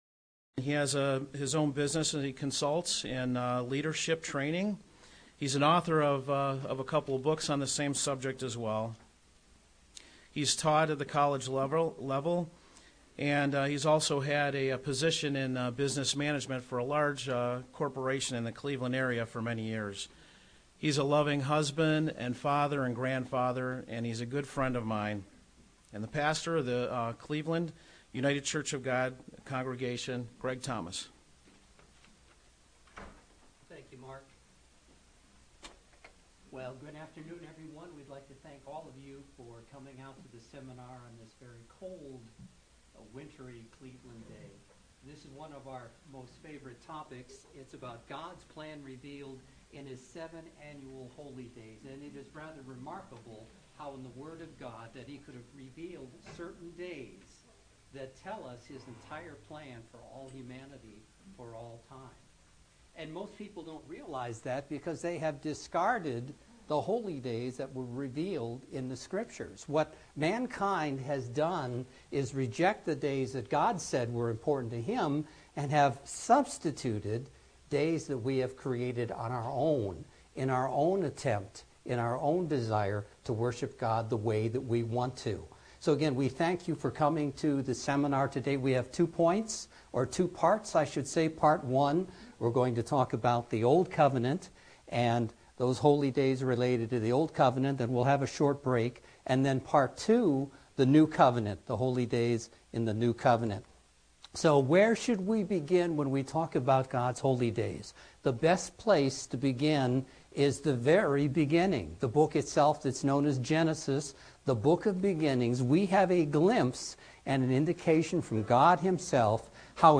Our Loving Creator God has outlined His marvelous plans for mankind through the Holy Days and the Sabbath, which He has instructed us to observe. Through this Kingdom of God seminar, let's look at the steps, and what they mean for our future, and the future of all mankind!